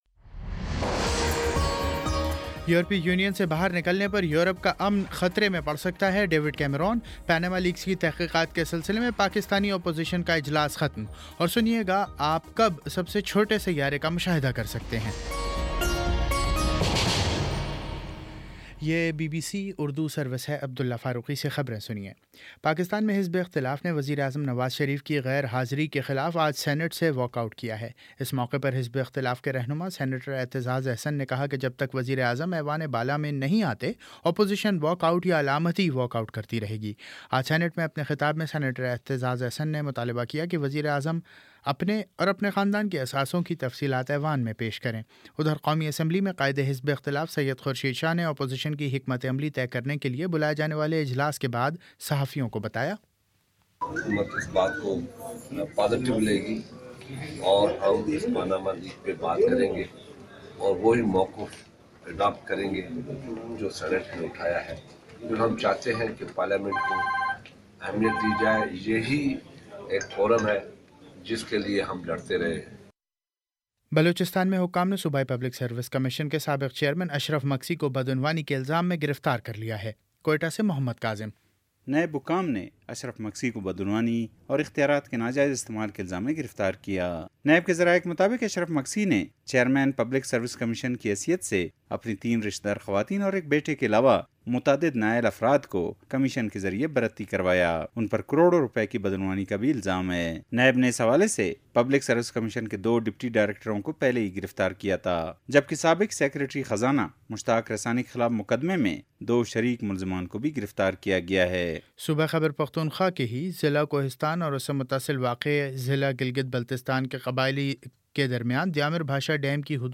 مئی 09: شام سات بجے کا نیوز بُلیٹن